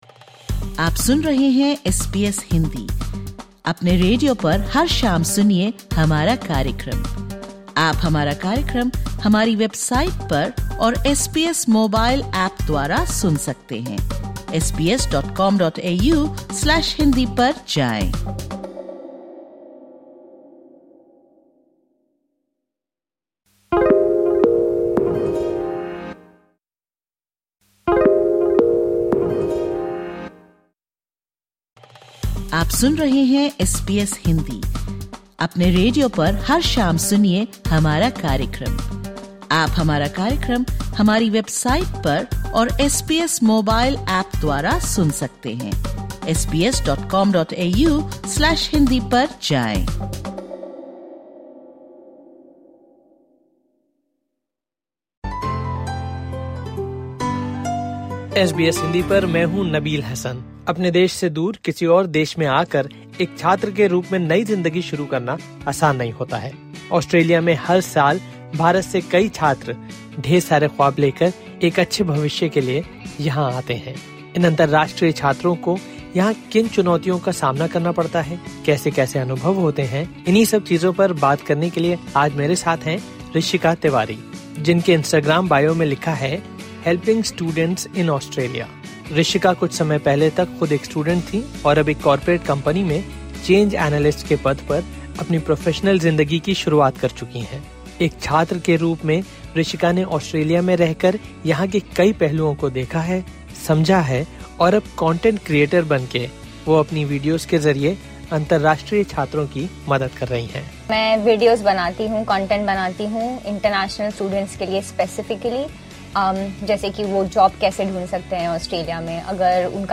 at the SBS Studios